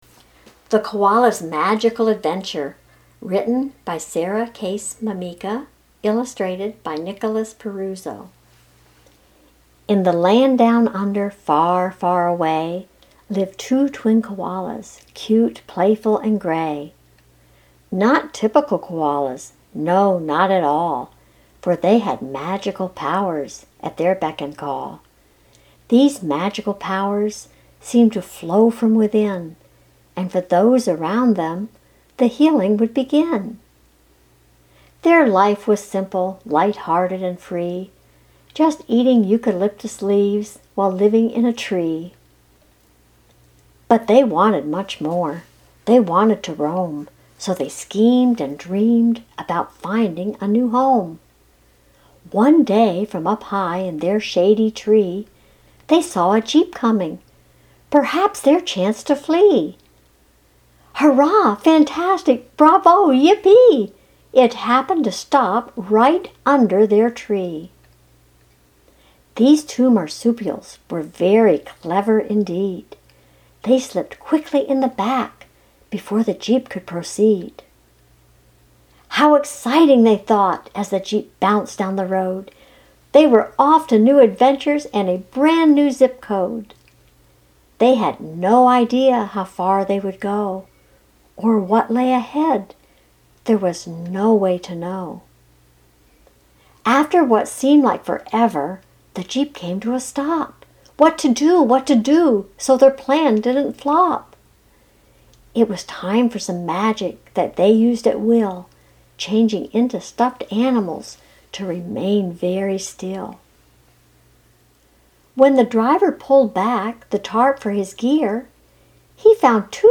Reading The Koalas' Magical Adventure